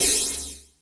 35CYMB01  -L.wav